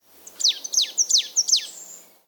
Art: Løvmeis (Poecile palustris)
Lokkelyd
Lyder: Løvmeisa har ulike lyder, blant annet en eksplosiv «pi-tju»-lyd, en gjentatt «tsjiu-tsjiu-tsjiu» og en ensformig sang: «tjipp-tjipp-tjipp-tjipp», nesten som om du vrir om på tenningen på en bil, men ikke får helt start.